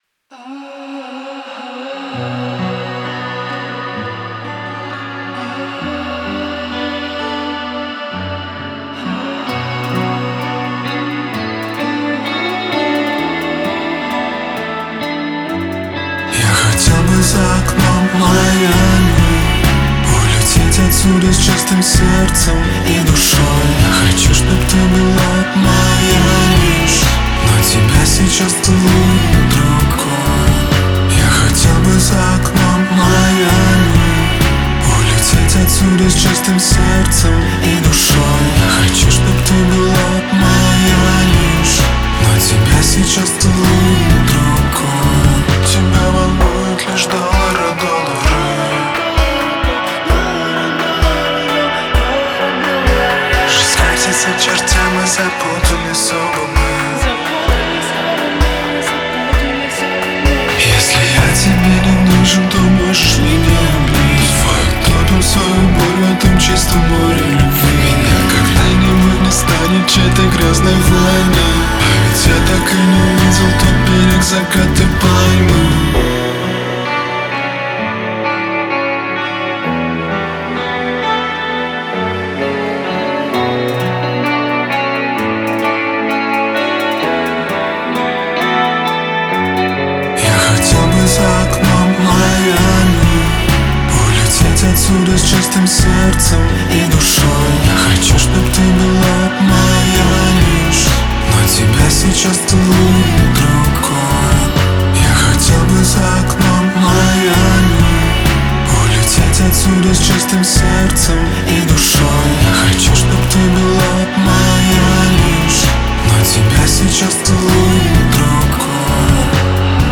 Вот все таки добил эти версии в третий вариант - и заказчику понравилось. Бомбанул ревера, поработал автоматизацией и все вроде всех устраивает - хотя я бы подсушил) Вложения 3.mp3 3.mp3 5,8 MB · Просмотры: 346